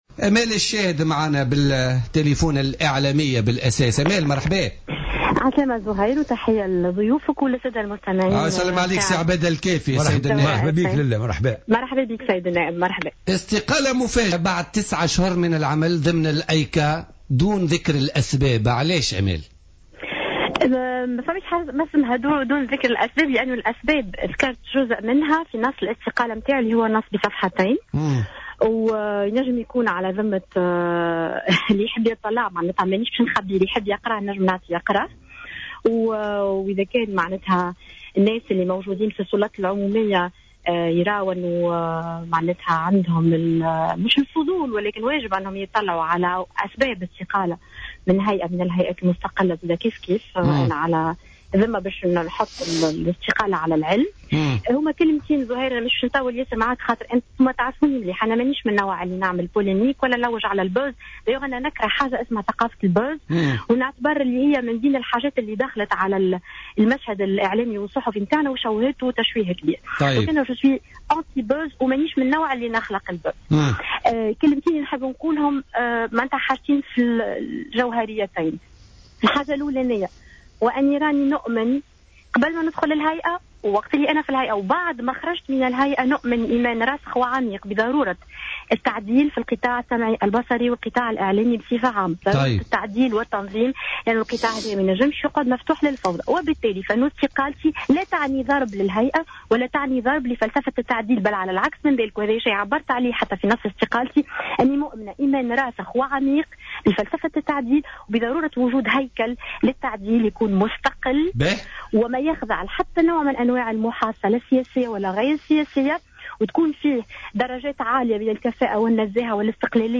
قالت الإعلامية وعضو الهيئة العليا المستقلة للإتصال السمعي البصري المستقيلة امال الشاهد في مداخلة لها في بوليتيكا الخميس أنها مع فكرة تعديل الإعلام و لكن ضد طريقة عمل الهايكا.